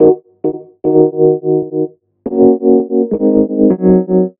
RI KEYS 1 -R.wav